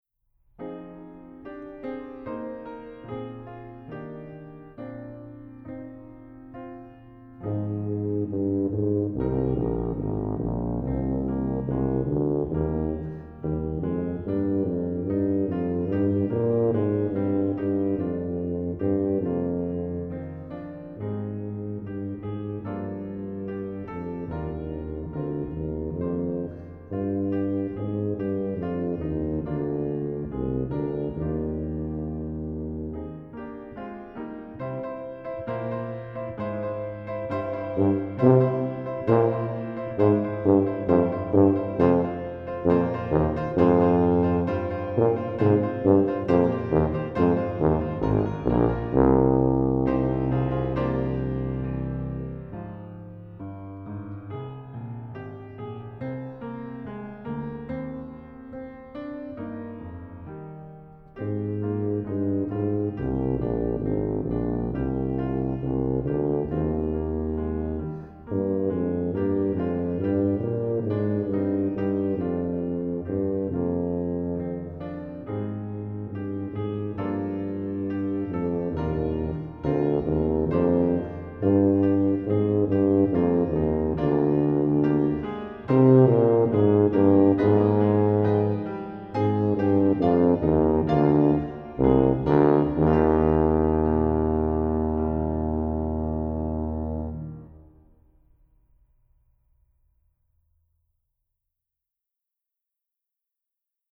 Style: Lyrical with contrasting Technical Bridge
Instrumentation: Tuba and Piano